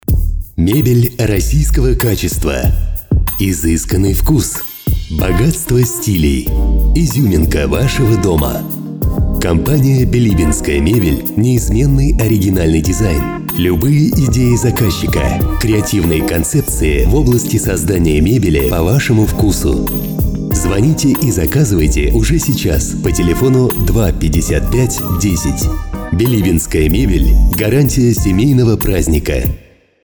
Билибинская мебель. Озвучание ролика Категория: Аудио/видео монтаж